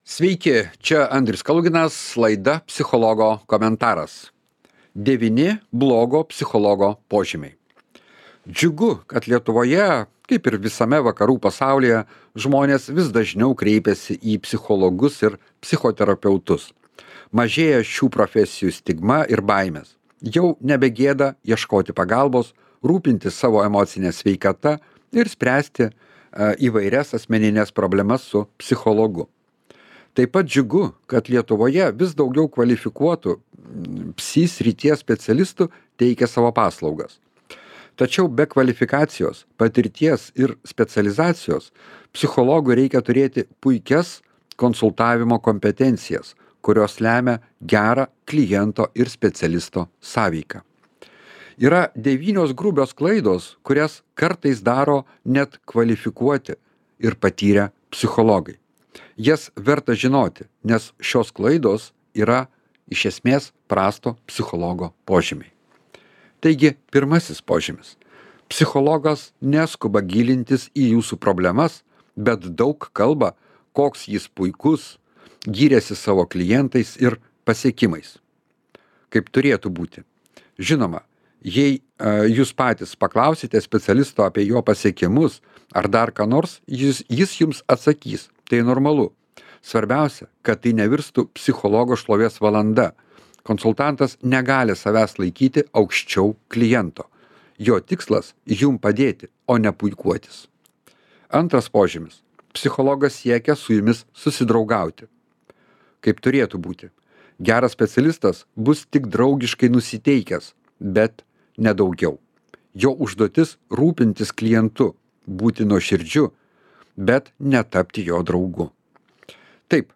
Psichologo komentaras